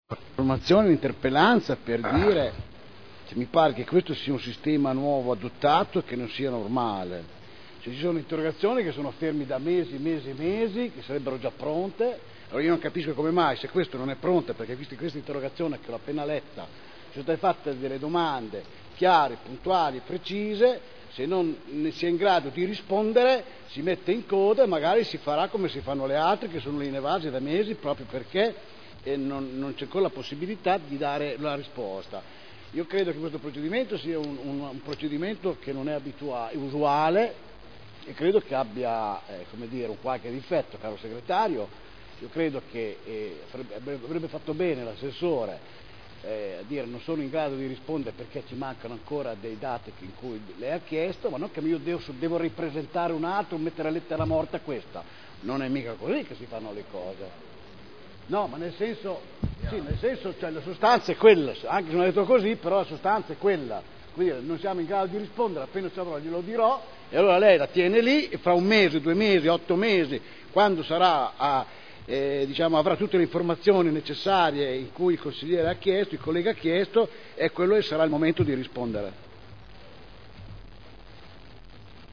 Mauro Manfredini — Sito Audio Consiglio Comunale